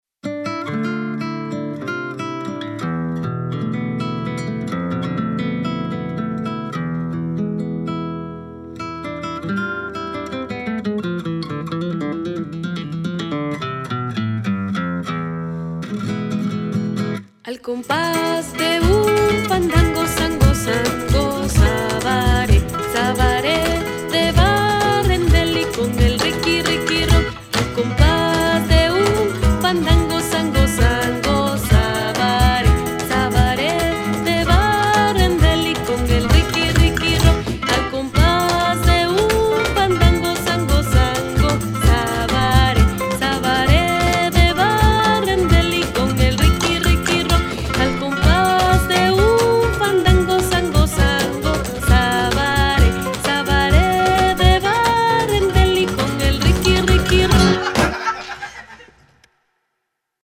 Tradicional español